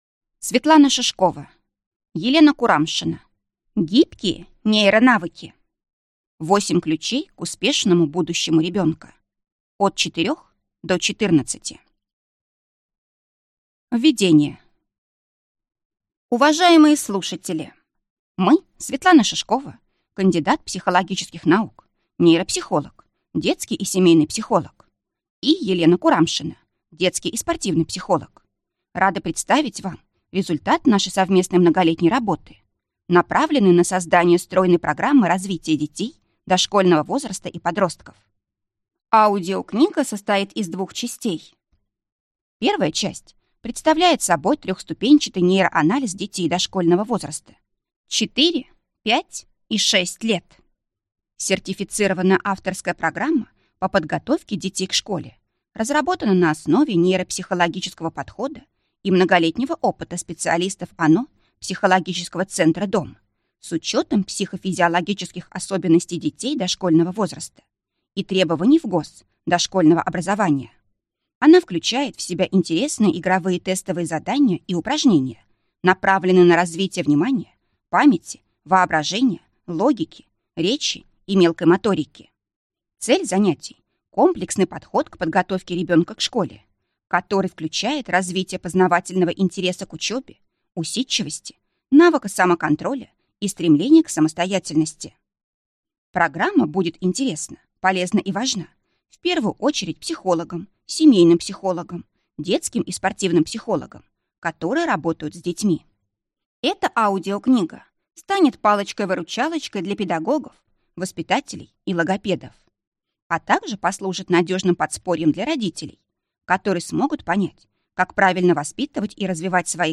Аудиокнига Гибкие нейронавыки. 8 ключей к успешному будущему ребенка! От 4 до 14 лет | Библиотека аудиокниг